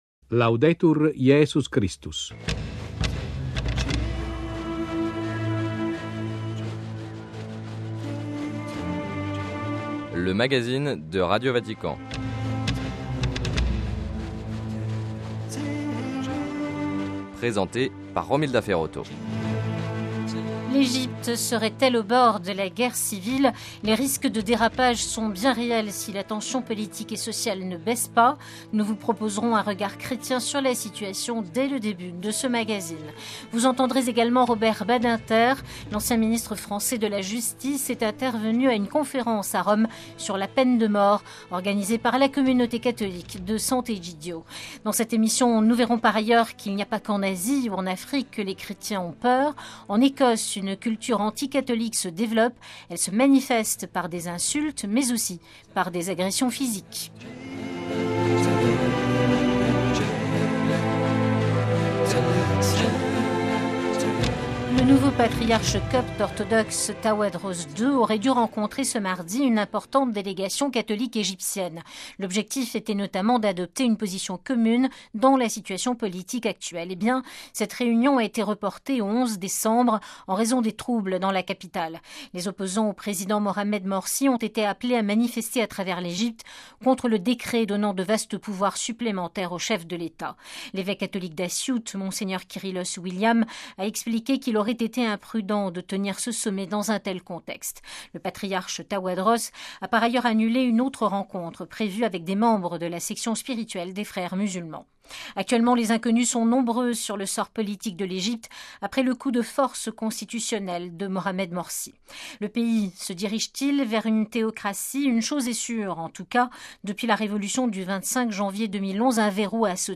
Sommaire : - Entretien avec un prêtre copte catholique sur les risques de guerre civile en Egypte.
- Entretien avec Robert Badinter, à l'occasion d'une conférence internationale sur la peine de mort organisée à Rome par la communauté de Sant'Egidio.